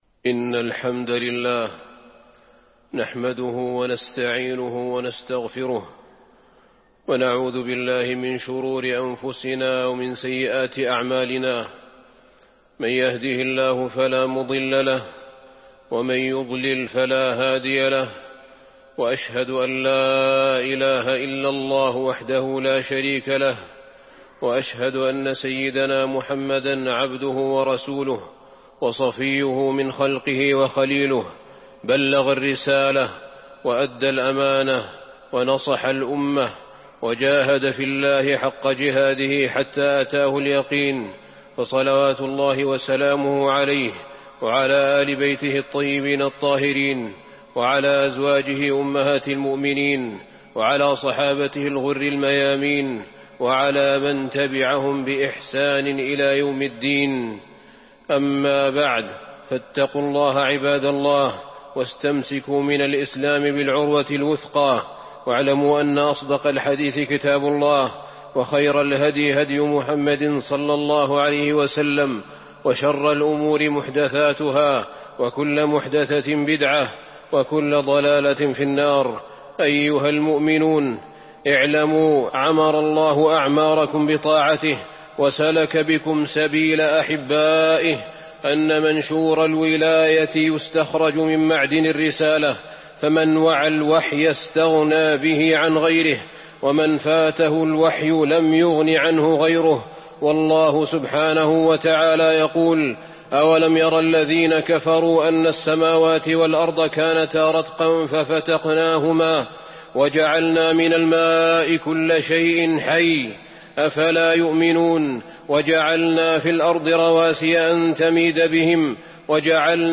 تاريخ النشر ٣٠ جمادى الآخرة ١٤٤٢ هـ المكان: المسجد النبوي الشيخ: فضيلة الشيخ أحمد بن طالب بن حميد فضيلة الشيخ أحمد بن طالب بن حميد وأن ليس للإنسان إلا ماسعى